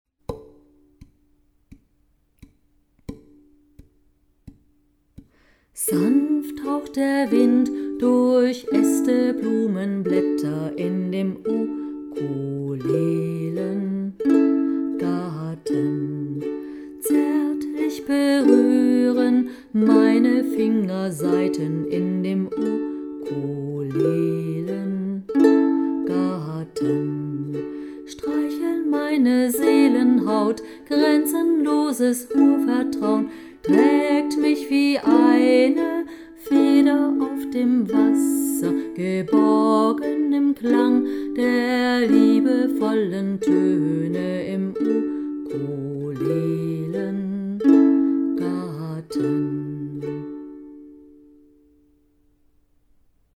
Normale Version zum Mitspielen: